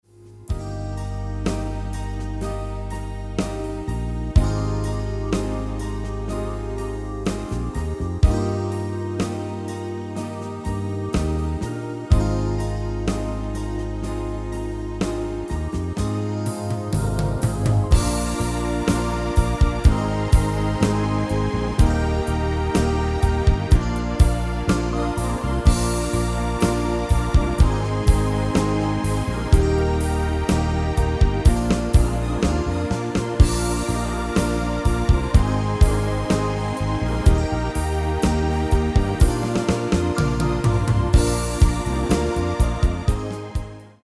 GM-Only MIDI File Euro 8.50
Demo's zijn eigen opnames van onze digitale arrangementen.